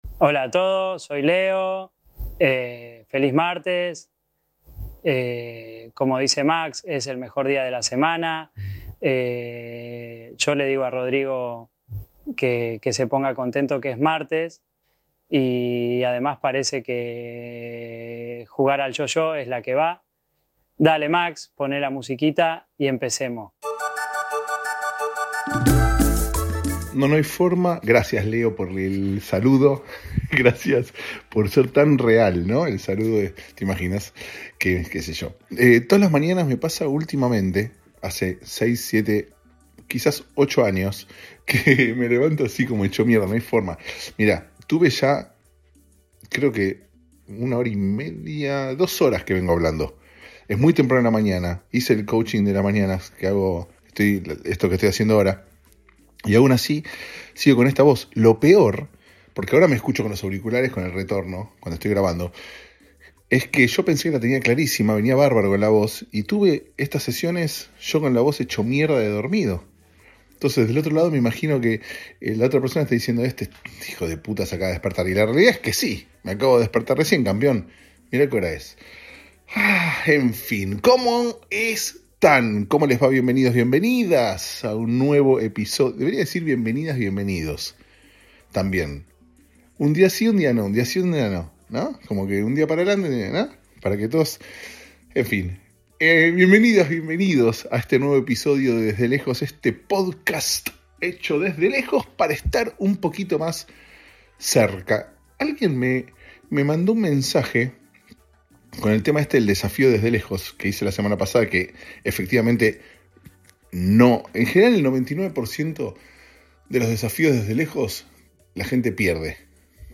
Un hermoso día en esta soleada y tremendamente calurosa Los Angeles.